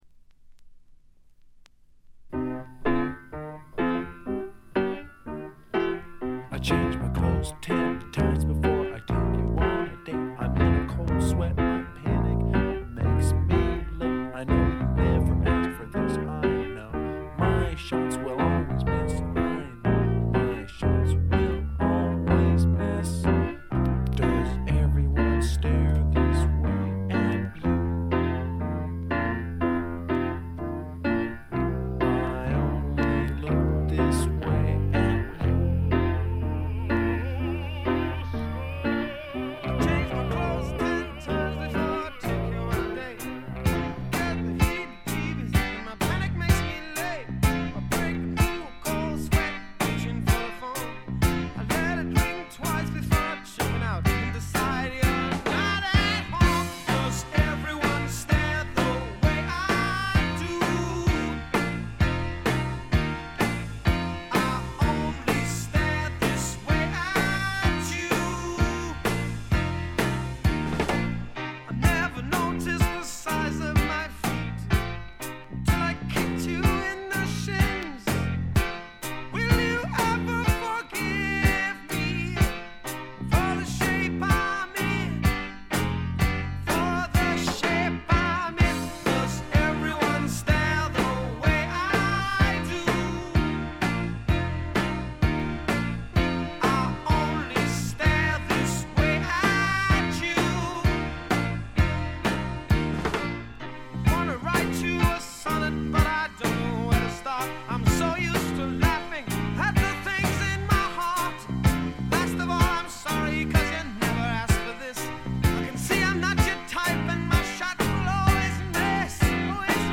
これ以外はほとんどノイズ感無し。
試聴曲は現品からの取り込み音源です。
Recorded At - Surrey Sound Studios